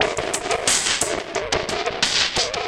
Index of /90_sSampleCDs/Spectrasonic Distorted Reality 2/Partition A/03 80-89 BPM